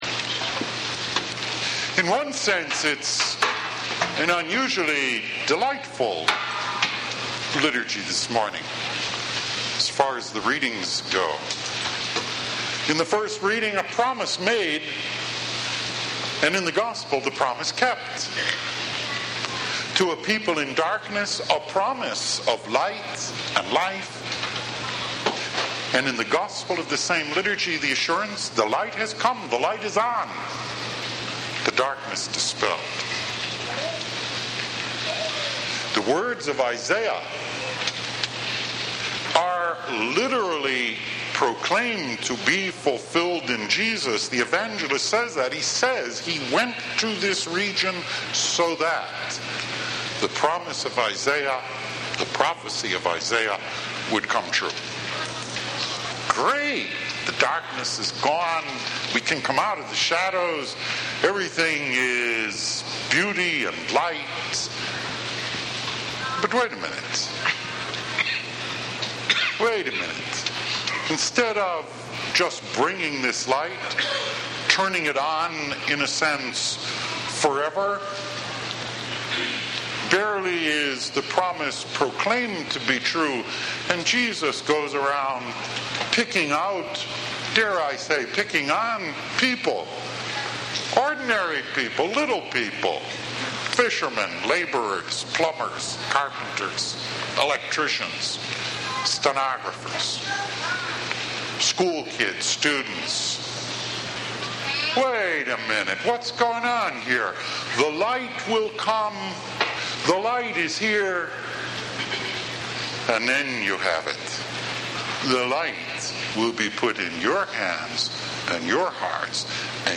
3rd Sunday of Ordinary Time « Weekly Homilies